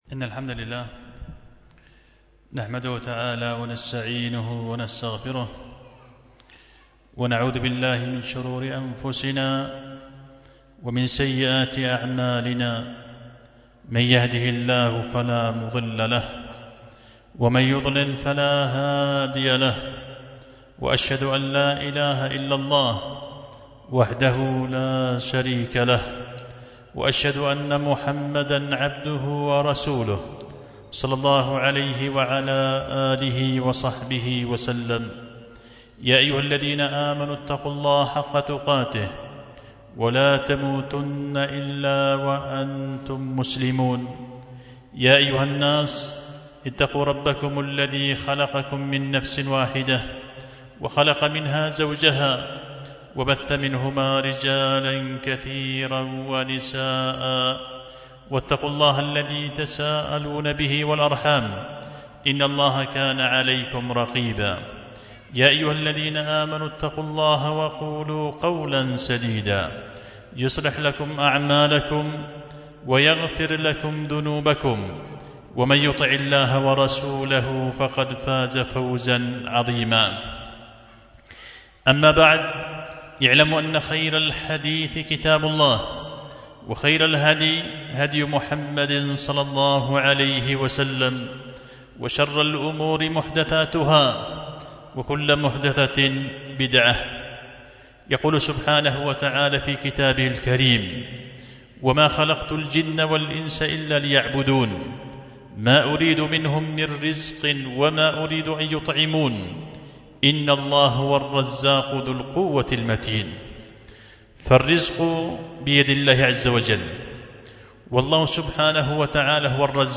خطبة جمعة بعنوان صدى الأصوات في بيان بعض أسباب البركات في الأموال والأقوات